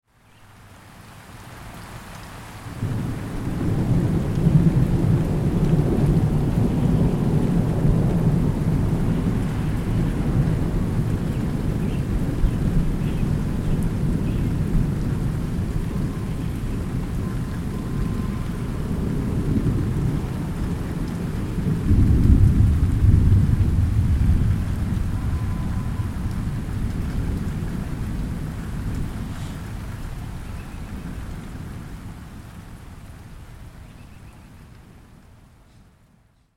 دانلود آهنگ طوفان 7 از افکت صوتی طبیعت و محیط
دانلود صدای طوفان 7 از ساعد نیوز با لینک مستقیم و کیفیت بالا
جلوه های صوتی